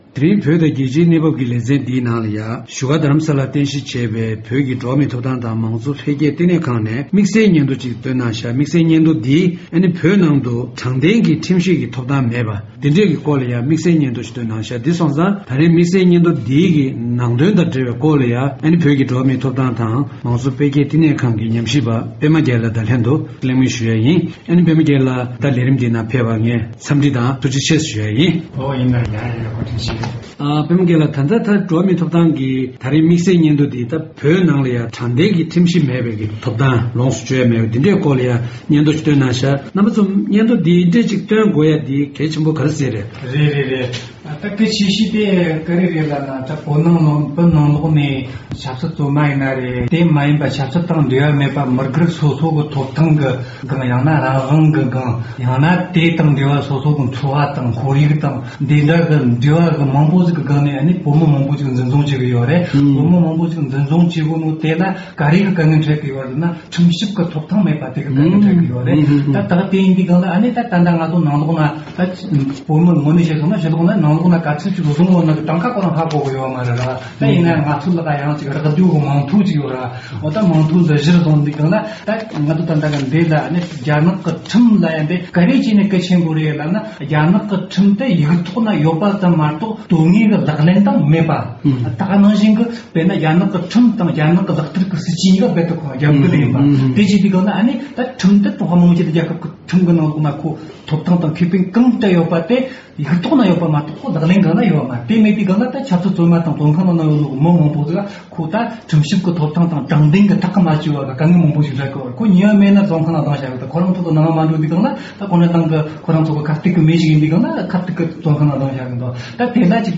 བོད་ནང་དྲང་བདེན་གྱི་ཁྲིམས་ཞིབ་ཐོབ་ཐང་མེད་པའི་སྐོར་གྱི་སྙན་ཐོའི་སྐོར་གླེང་མོལ་ཞུས་པ།